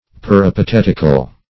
Peripatetical \Per`i*pa*tet"ic*al\
peripatetical.mp3